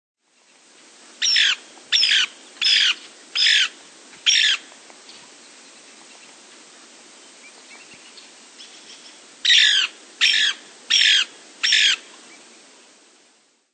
Lewis's Woodpecker
Bird Sound
Call a series of "churs".
Lewis'sWoodpecker.mp3